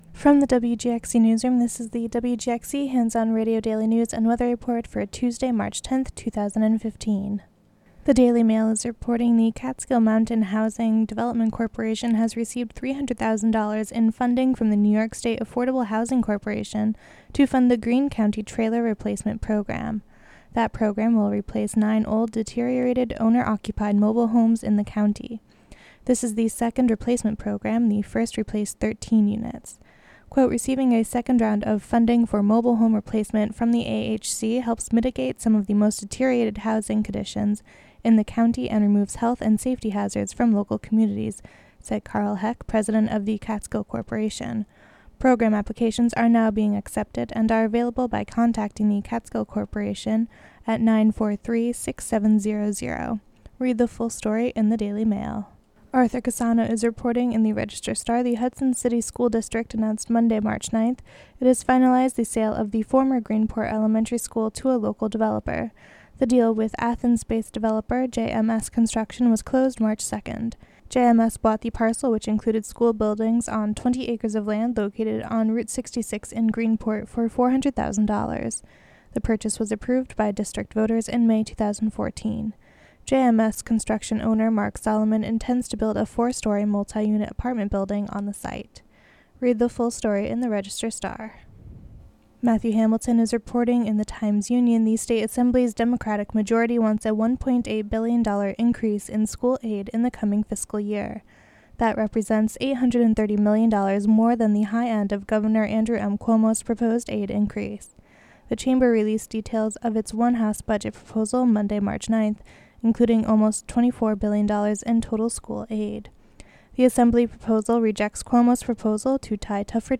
Local news and weather for Tuesday, March 10, 2015.